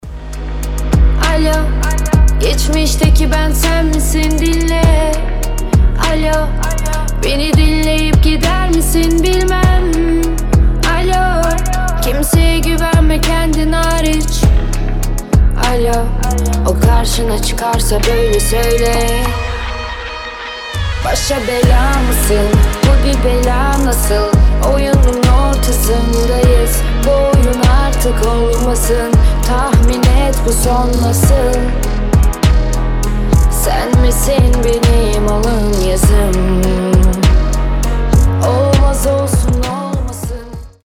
• Качество: 320, Stereo
красивые
лирика
женский голос
спокойные